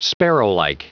Prononciation du mot sparrowlike en anglais (fichier audio)
Prononciation du mot : sparrowlike